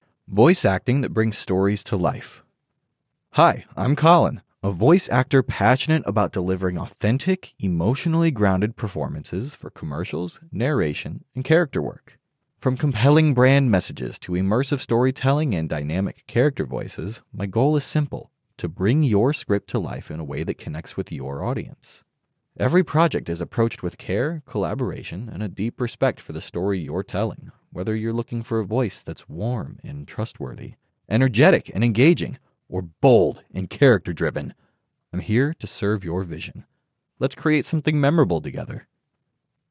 Whether you’re looking for a voice that’s warm and trustworthy, energetic and engaging, or bold and character-driven, I’m here to serve your vision.